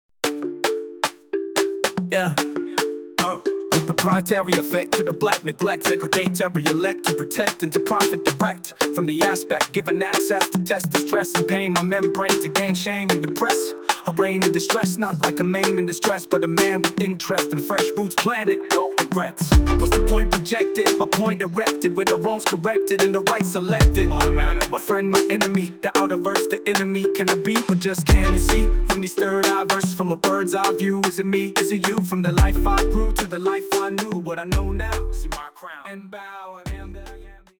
Reggae (island)